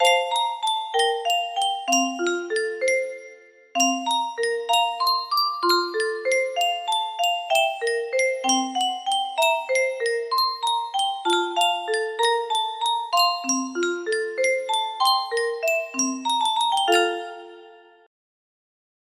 Yunsheng Music Box - Mozart's Lullaby Y143 music box melody
Full range 60